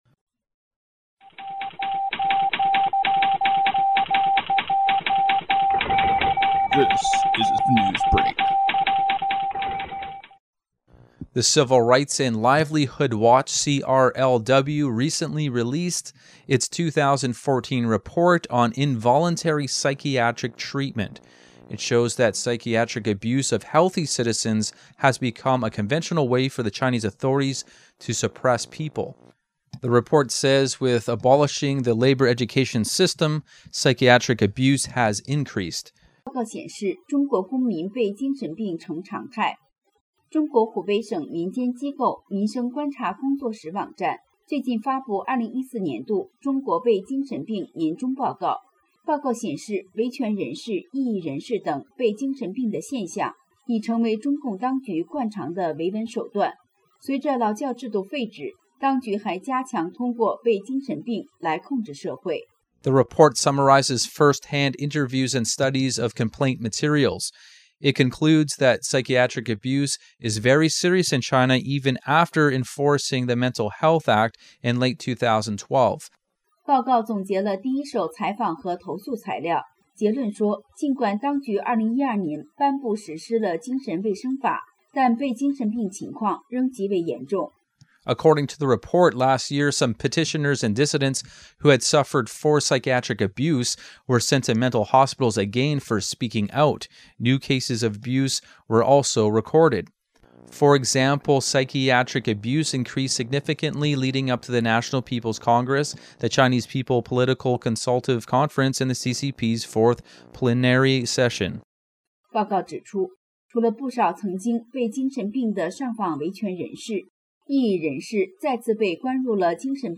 Type: News Reports